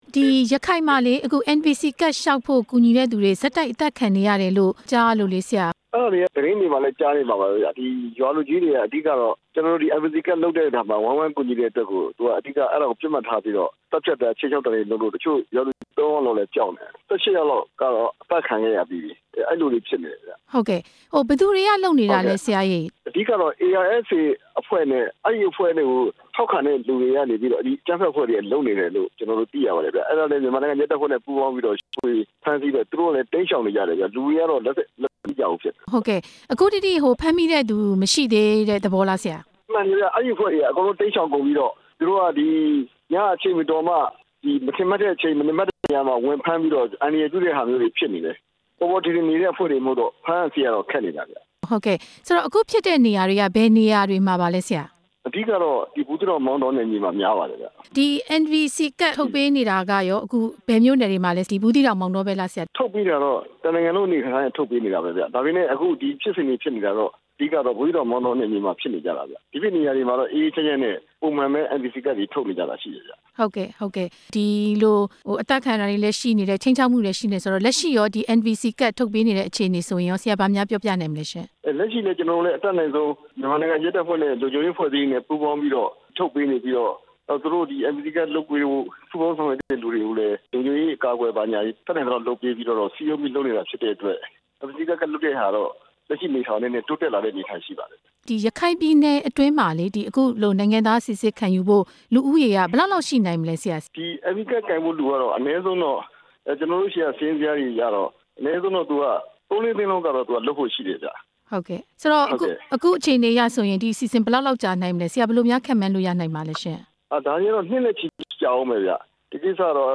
NVC ကဒ်လုပ်ရာမှာ ပူးပေါင်းဆောင်ရွက်သူ ၁၈ ဦး အသတ်ခံရကြောင်း မေးမြန်းချက်